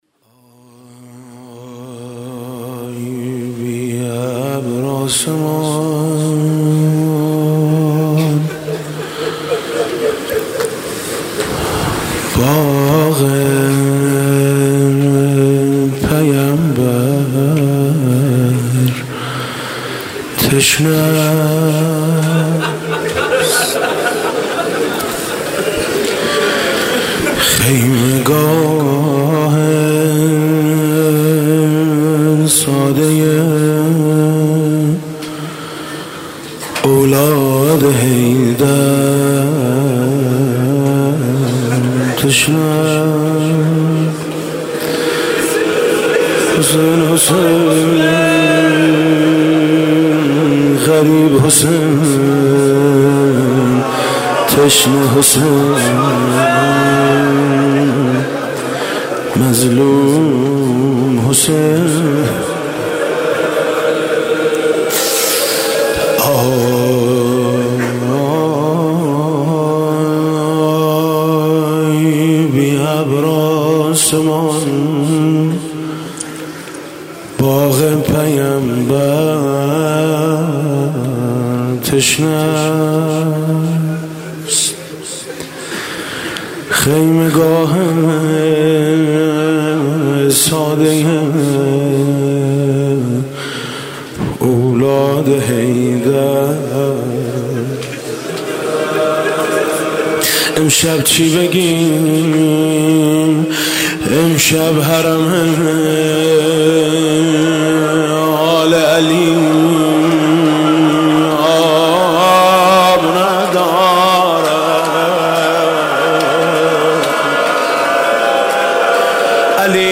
دانلود مداحی جدید حاج میثم مطیعی – شب هفتم محرم ۱۳۹۷ – مجله نودیها
روضه: خیمه گاه ساده‌ی اولاد حیدر تشنه است